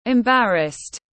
Embarrassed /ɪmˈbærəst/